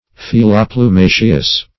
Search Result for " filoplumaceous" : The Collaborative International Dictionary of English v.0.48: Filoplumaceous \Fil`o*plu*ma"ceous\, a. (Zool.) Having the structure of a filoplume.
filoplumaceous.mp3